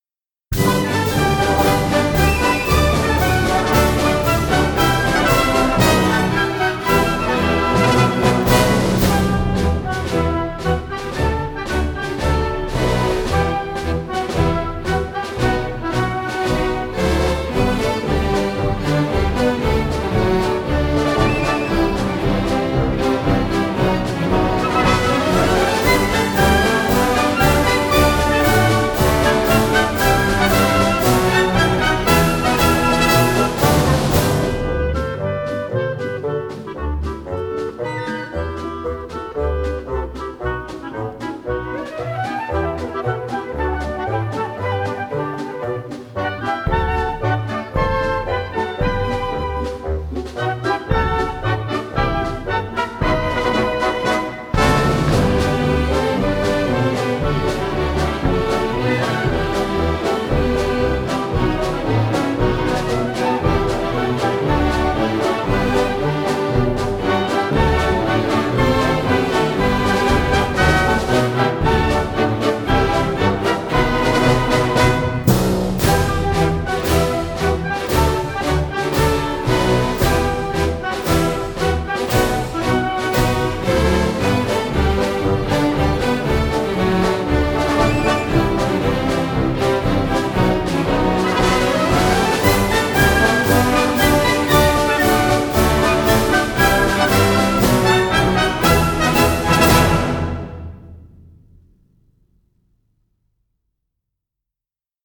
Pista sin coros del Himno versión corta (1:45)